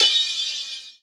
D2 RIDE-05-R.wav